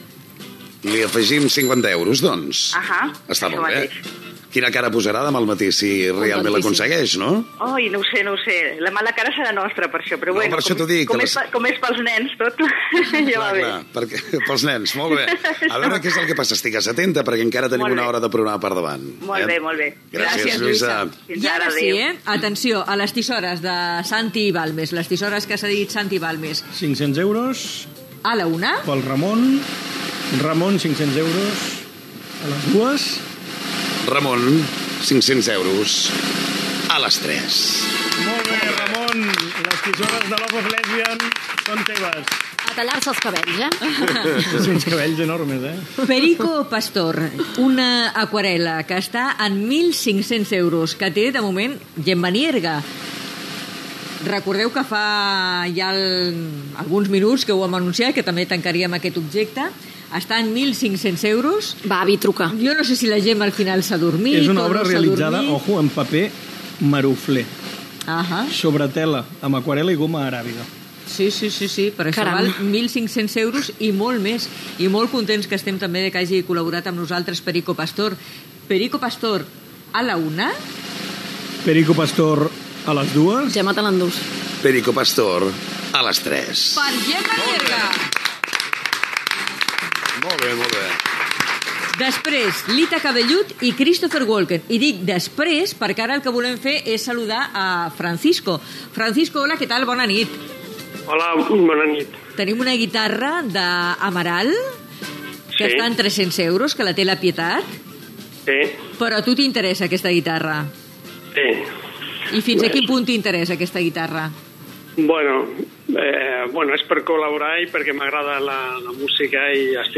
Fragment de l'hora final de la 50ena edició: adjudicació d'alguns objectes i trucada dels oients per pujar per altres.